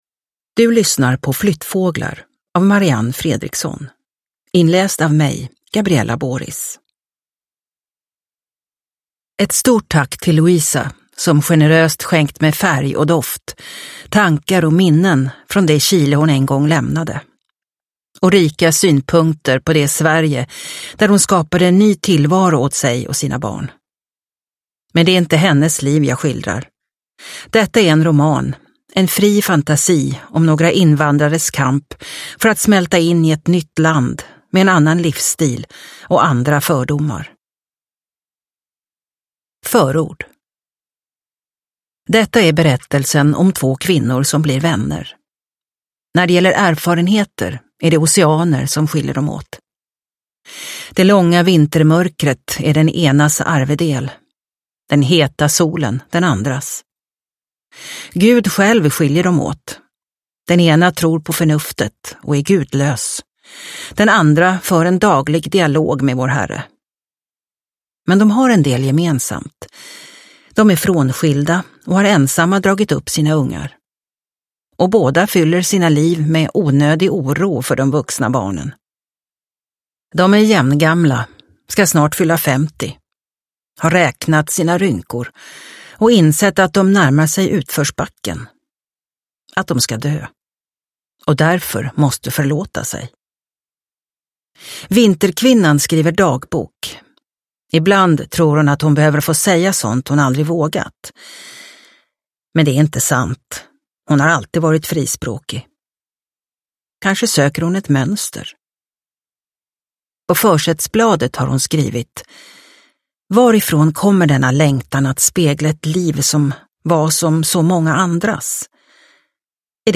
Flyttfåglar – Ljudbok – Laddas ner